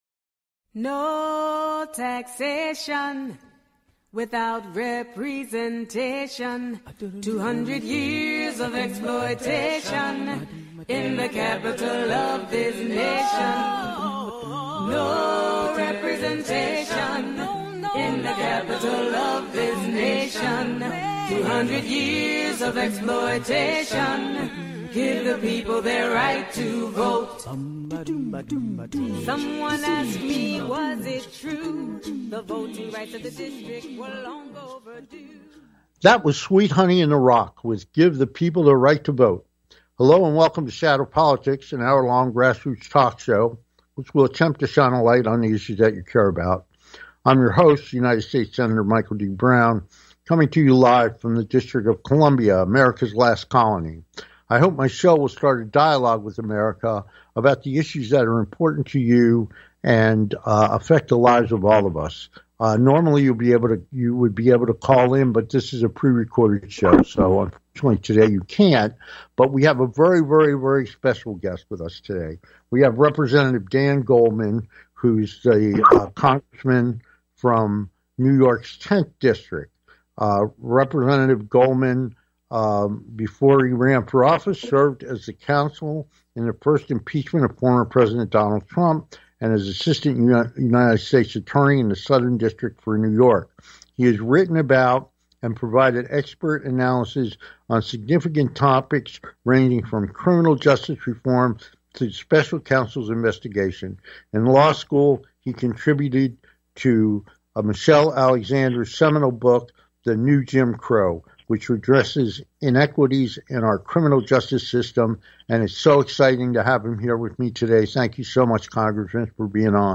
Trump This - With Guest Congressman - Dan Goldman Lead Counsel in the First Impeachment of Former President Donald Trump